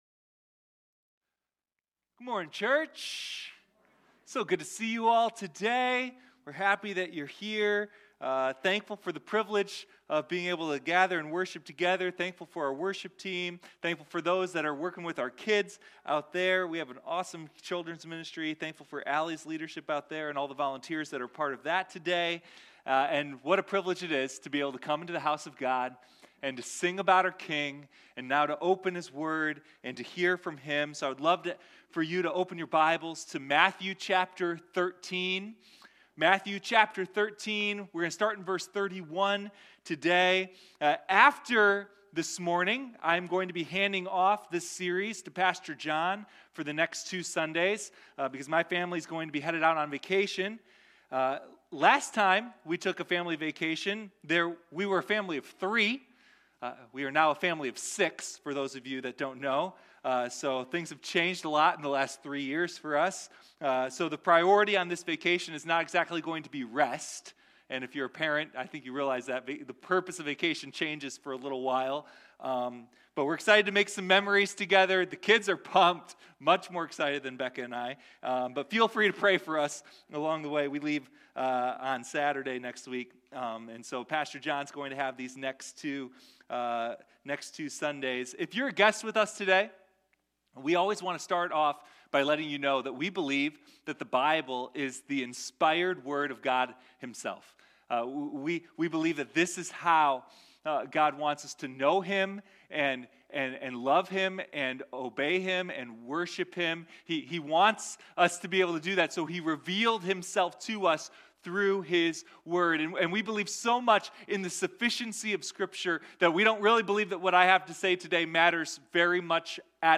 Sunday Morning Stories of the Kingdom